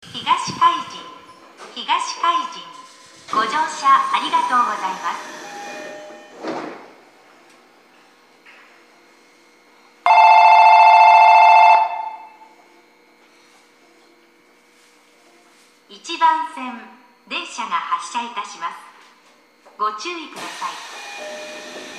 駅放送
到着発車 ベルの音が大きすぎる ベルの音に比して自動放送の音量は小さめです。